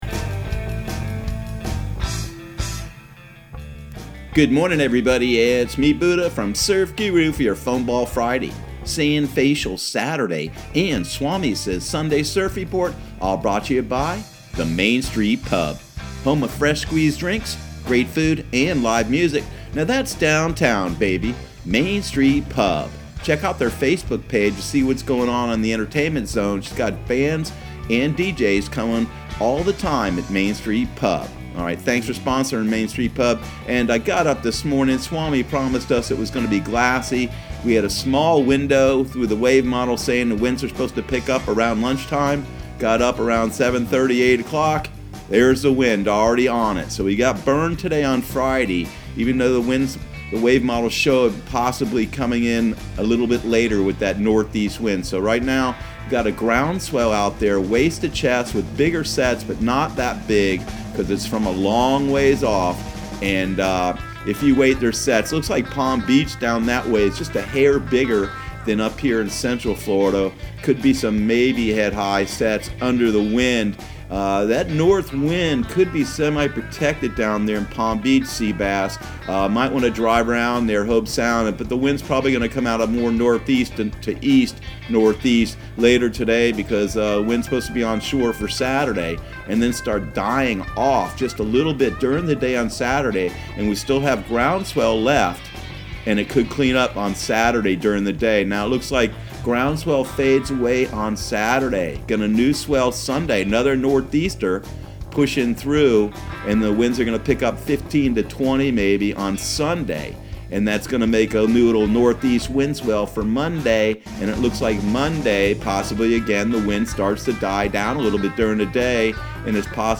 Surf Guru Surf Report and Forecast 11/01/2019 Audio surf report and surf forecast on November 01 for Central Florida and the Southeast.